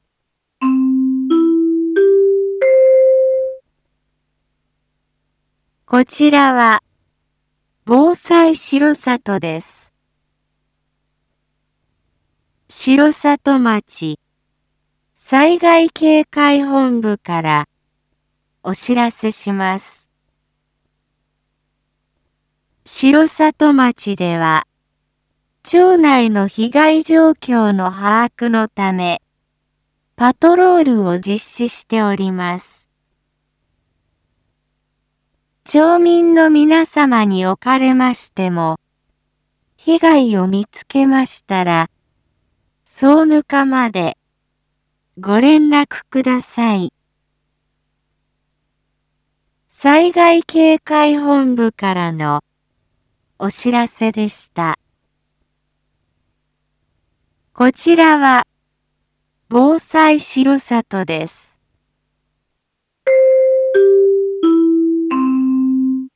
Back Home 一般放送情報 音声放送 再生 一般放送情報 登録日時：2022-04-19 08:58:07 タイトル：R4.4.19 震度5弱（緊急） インフォメーション：こちらは、防災しろさとです。